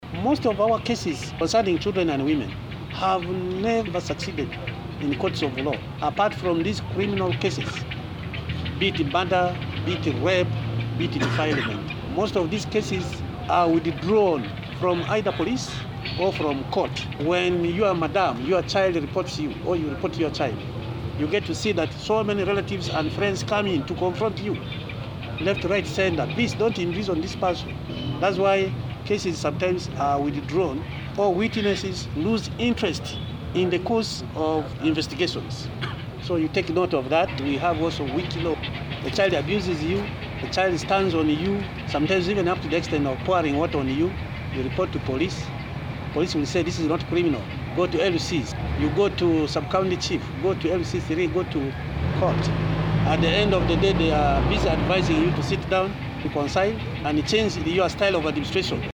Police in an engagement with journalists of west Nile organized by MEMPROW